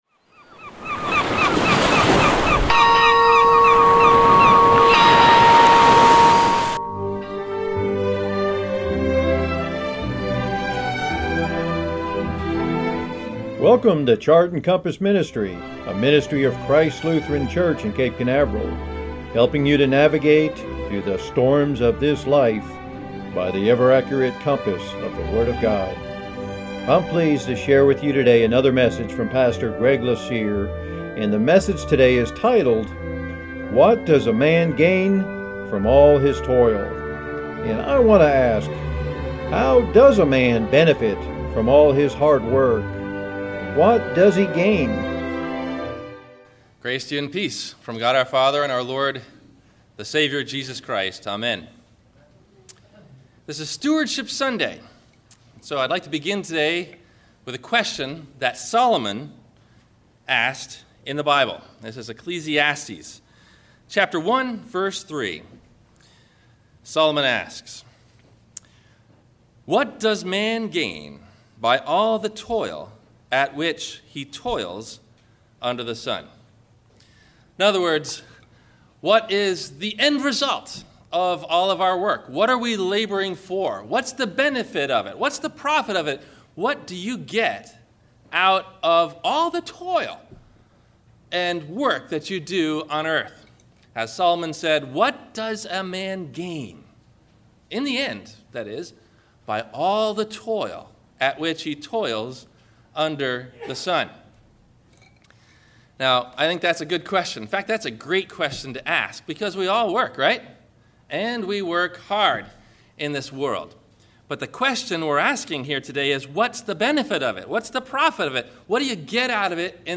What Does A Man Gain From All His Toil? – WMIE Radio Sermon – August 31 2015